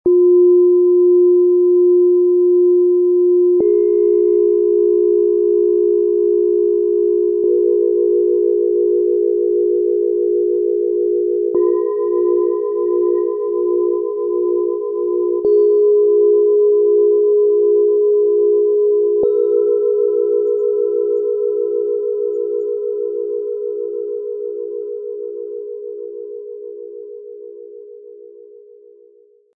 Fließend leben, Neues wagen, klar sprechen und sich geborgen fühlen - Set aus 3 Planetenschalen, Ø 11,5 -14,4 cm, 1,49 kg
Sanft schwingende Töne öffnen Raum für Leichtigkeit und Kreativität.
Im Sound-Player - Jetzt reinhören hören Sie den Original-Ton genau dieser Schalen – so, wie sie gemeinsam im Set erklingen.
Tiefster Ton: Wasser
Bengalen Schale, Matt, 14,4 cm Durchmesser, 7,6 cm Höhe
Mittlerer Ton: Uranus, Merkur
Höchster Ton: Mond